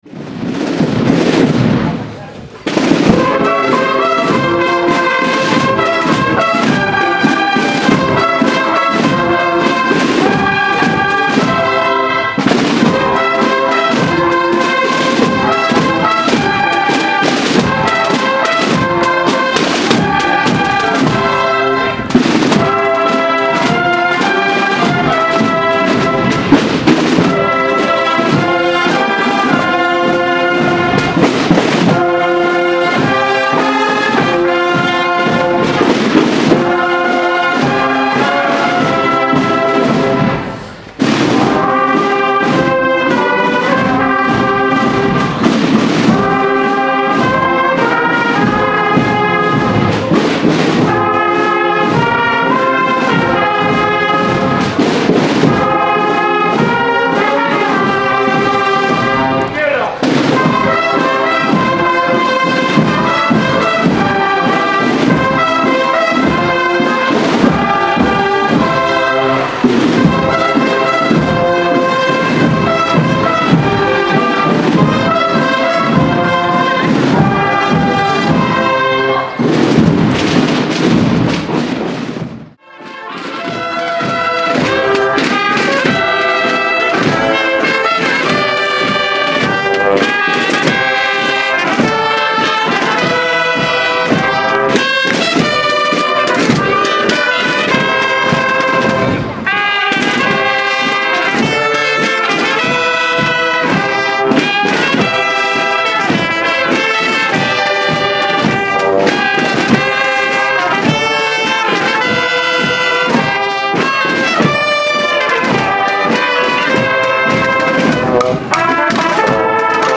Fiesta Santa Verónica - 2014
Tras la Misa
Previamente ofrecieron un pasacalles.
Ya una vez abierto el chiringuito comenzó la actuación del Duo Boulevard.